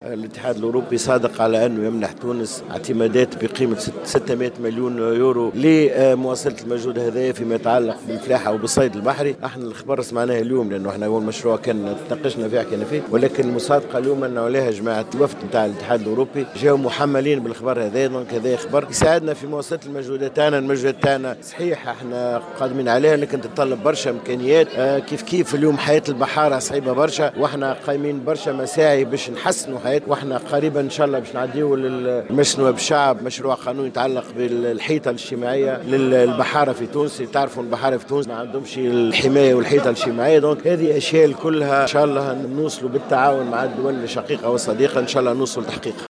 أكد وزير الفلاحة سمير الطيب في تصريح لمراسل الجوهرة "اف ام" اليوم الثلاثاء 22 جانفي 2019 أن الإتحاد الأوروبي صادق على منح تونس اعتمادات ب600 مليون أورو لدعم الفلاحة والصيد البحري.